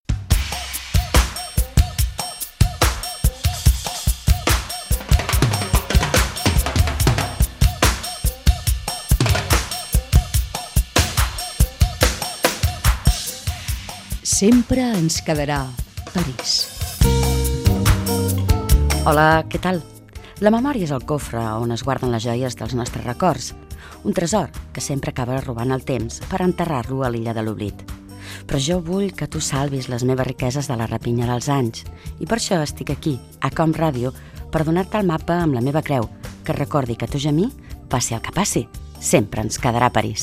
Indicatiu del programa, presentació del programa
Entreteniment